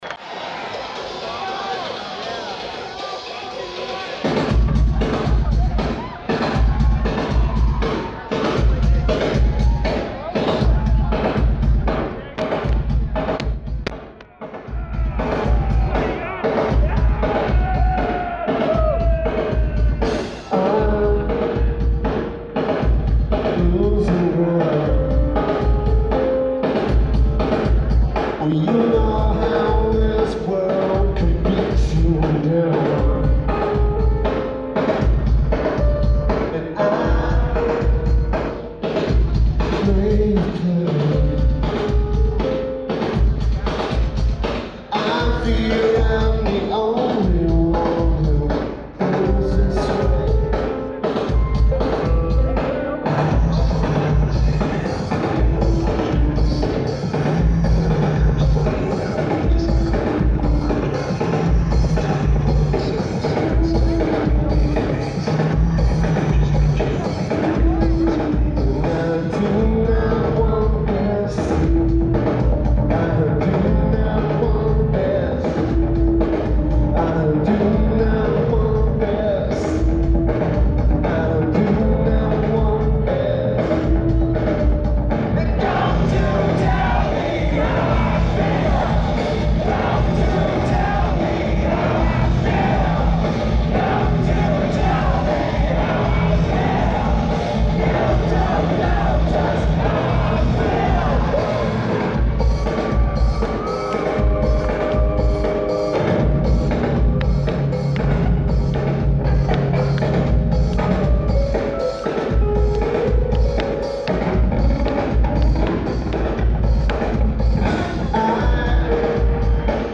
Lineage: Audio - AUD (bootlegMIC + Samsung Galaxy S5)
Notes: Mono recording.